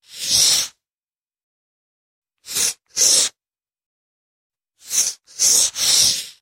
Звуки маркера